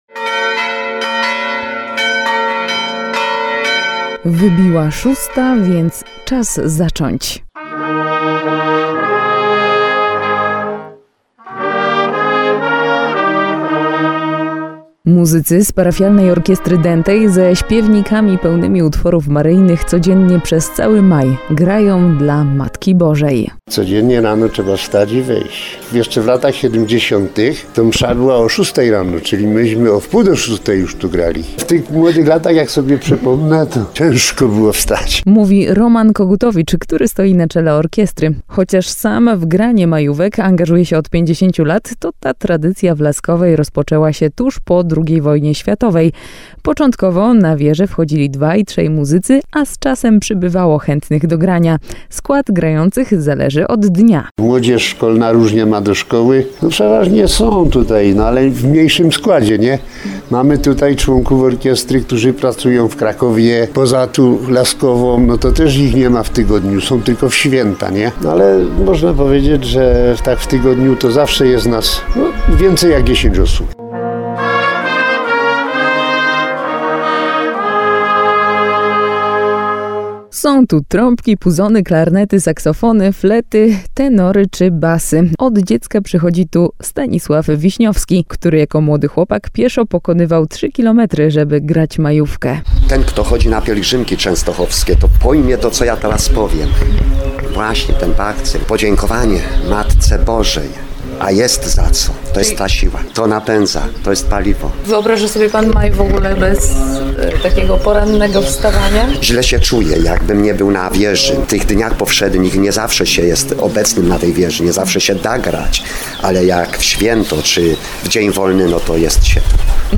Każdego dnia muzyka rozbrzmiewa też z wieży kościoła Najświętszego Imienia Maryi w Laskowej, w powiecie limanowskim. Na dzwonnicę wchodzą członkowie Parafialnej Orkiestry Dętej.
Są tu trąbki, puzony, klarnety, saksofony, flety, tenory czy basy.